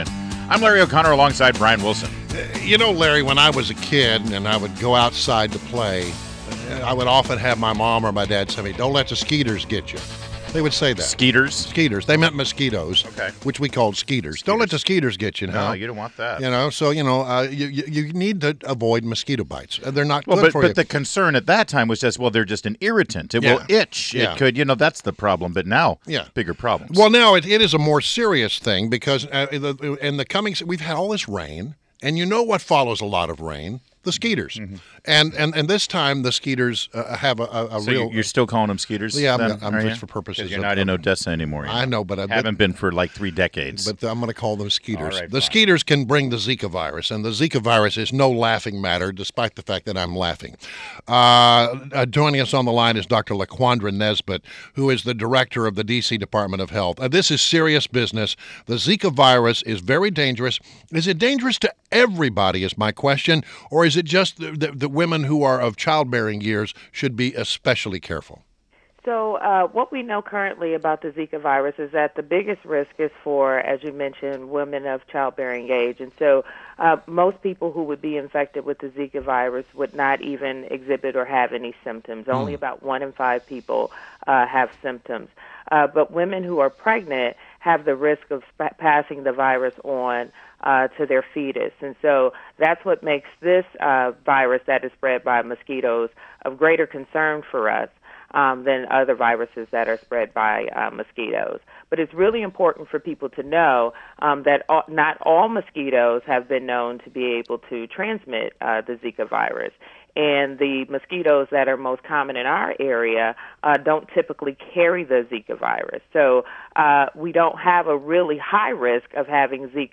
WMAL Interview: DR. LAQUANDRA NESBITT 05.13.16
INTERVIEW — DR. LAQUANDRA NESBITT, MD, MPH – DIRECTOR OF DC DEPARTMENT OF HEALTH – discussed the Zika virus: what does Zika do to the body, how many cases have we seen in the area of people with Zika, how can it be prevented and what the city is doing to combat it.